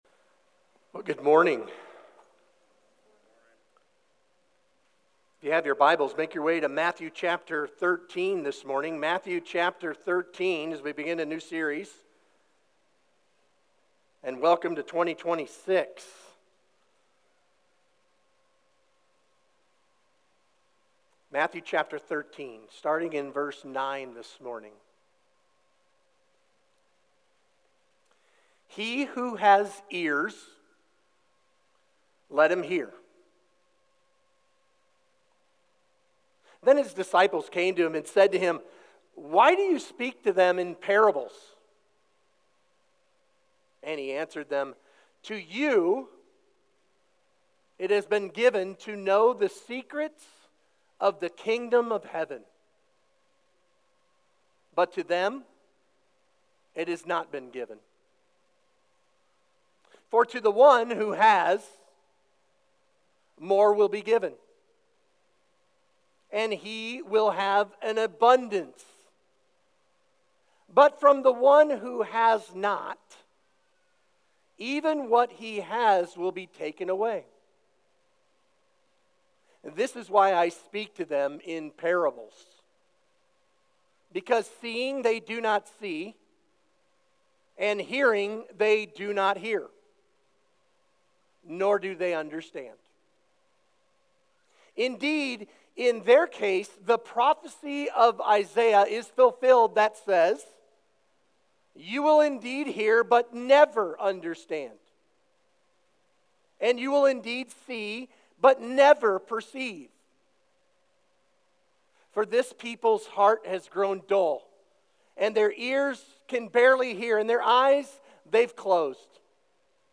Sermon Questions Read Matthew 13:9–10 What are Parables? Insight: Parables are short stories drawn from everyday life that communicate spiritual truth.